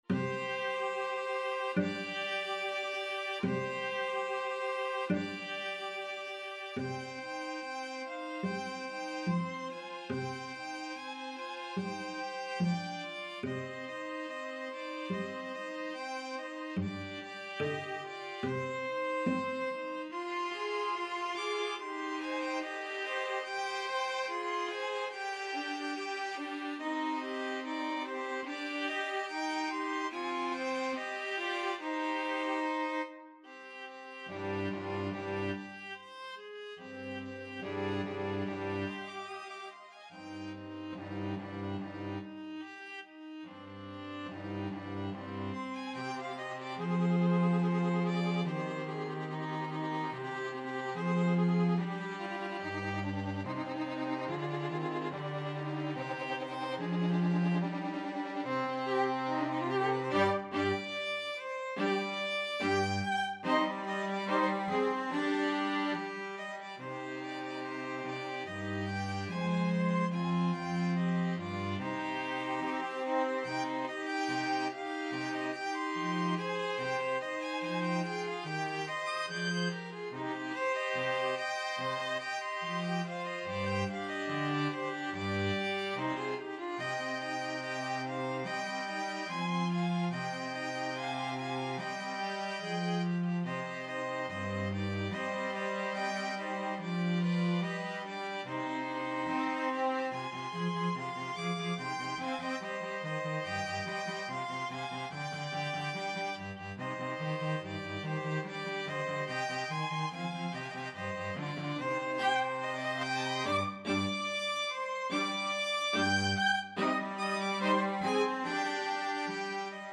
B. String Quartet (String Orchestra, Cello Q.)